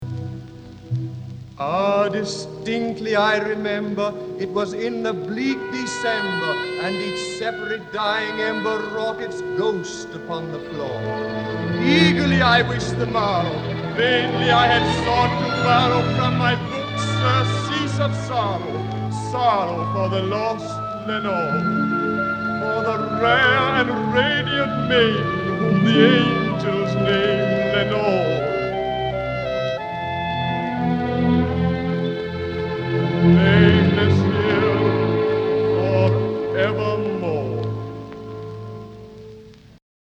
In imagining the sound design of the first Talking Books, Irwin took inspiration from popular records that combined spoken word with sound effects, such as a
dramatic reading of Edgar Allen Poe’s “The Raven” accompanied by orchestral music.